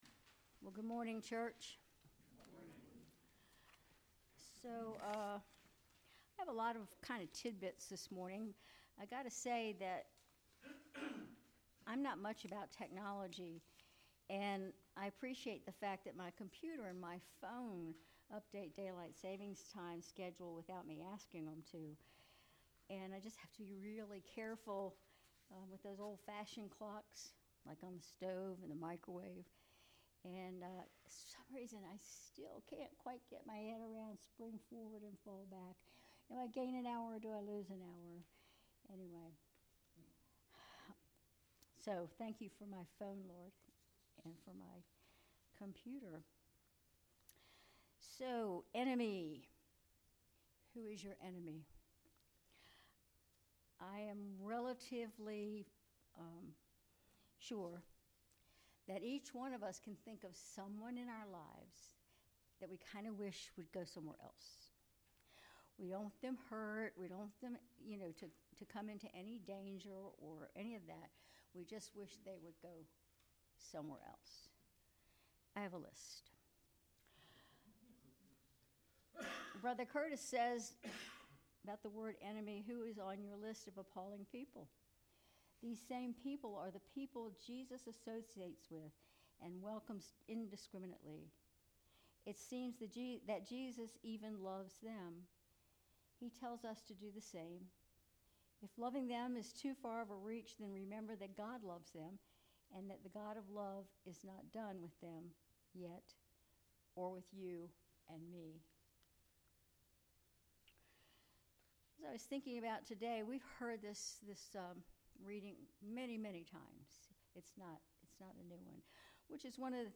Sermon March 9, 2025